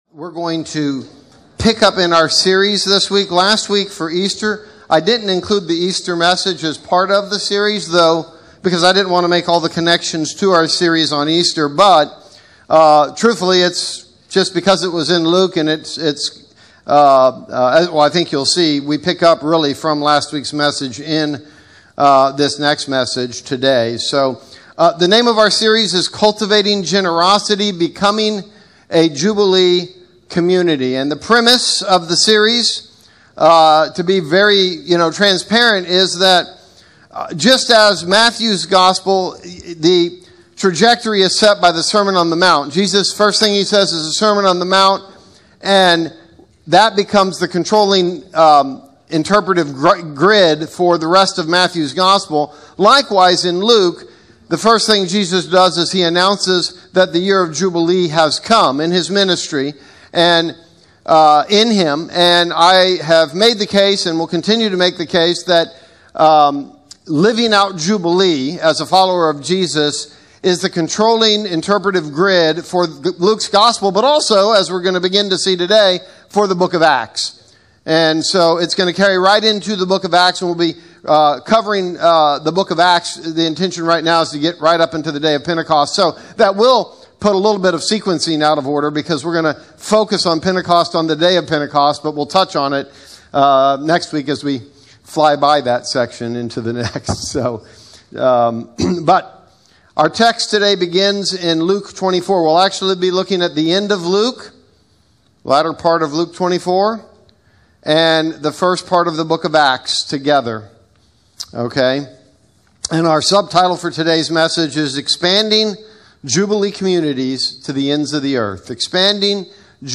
Gulf Coast Community Church Podcast